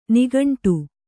♪ nigaṇṭu